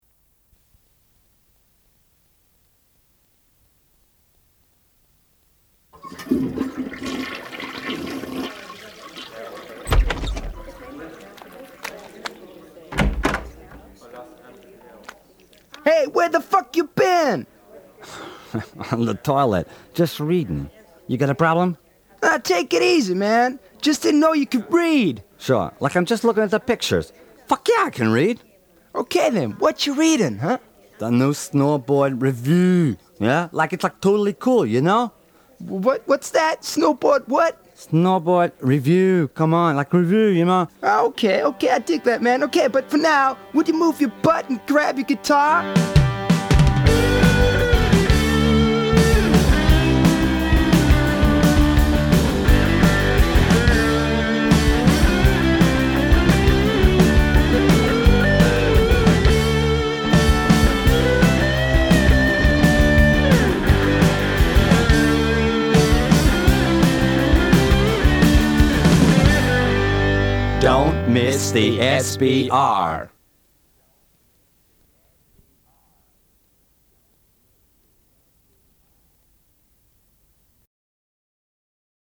SNOW IN THE CITY - EVENT am Wiener Karlsplatz gespielt.